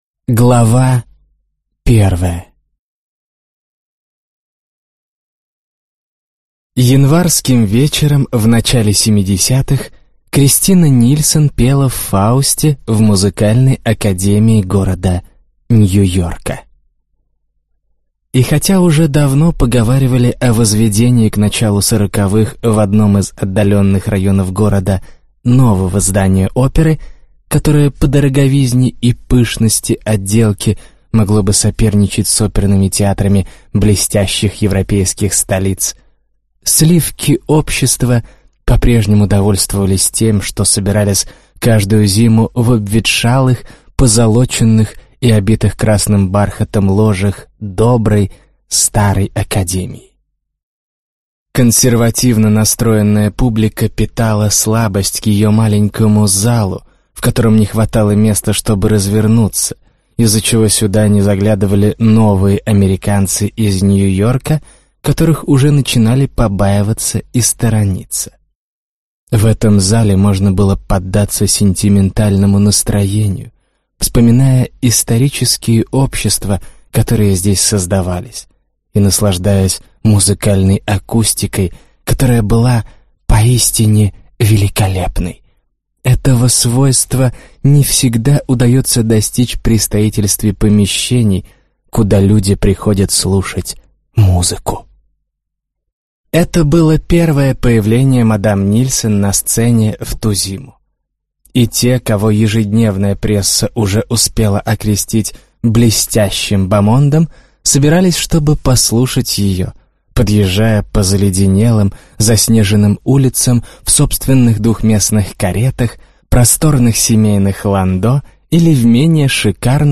Аудиокнига Век невинности | Библиотека аудиокниг